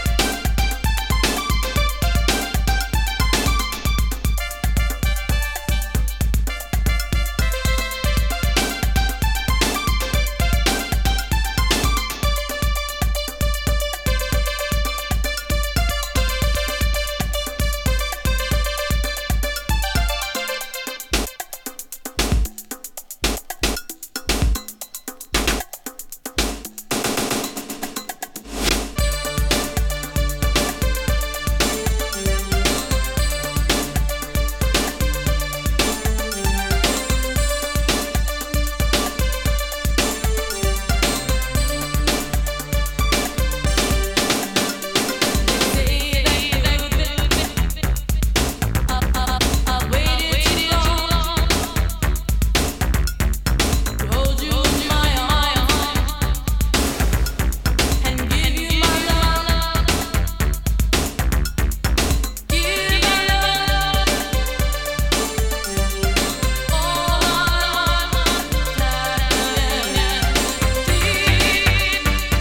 Edit炸裂のドラム、哀愁メロディ、Latin Freestyle特有のシンセフレーズが素晴らしい！